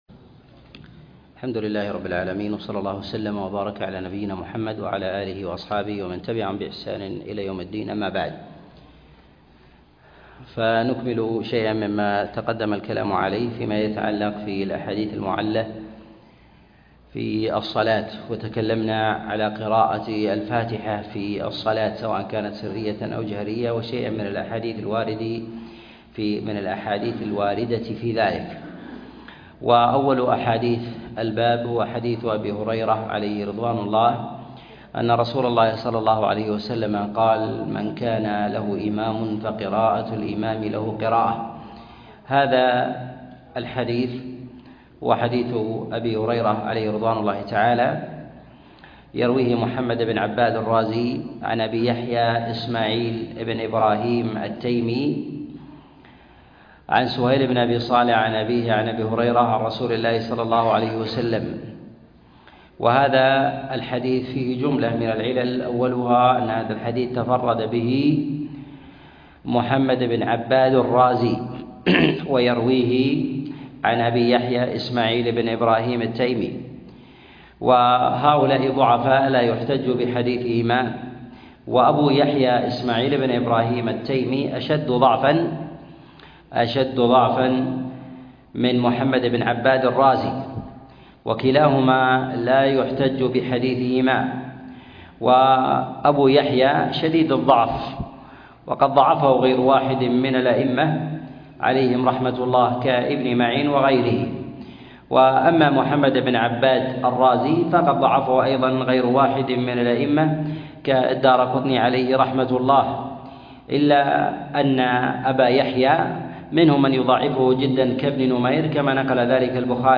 الأحاديث المعلة في الصلاة الدرس 32